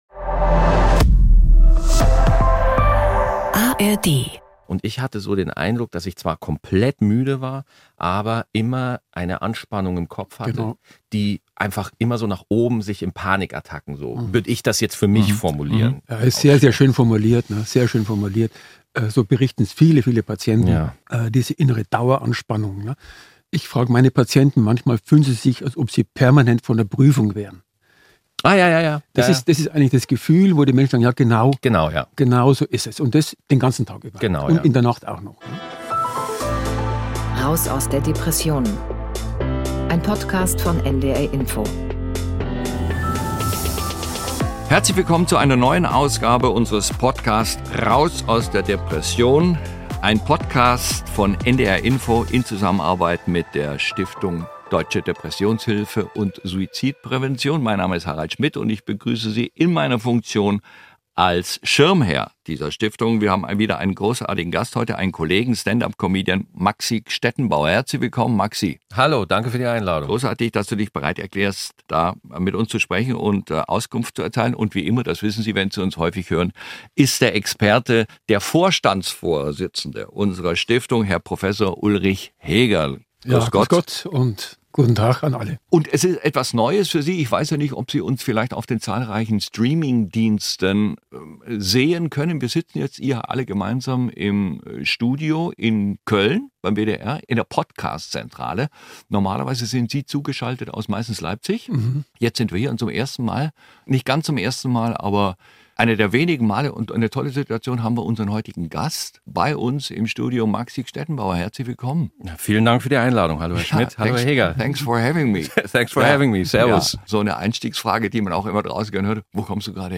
Mit seiner wissenschaftlichen Expertise zu Ursachen, Auslösern und Therapien der Depression beantwortet Professor Dr. Ulrich Hegerl wie immer Fragen und liefert Einordnungen.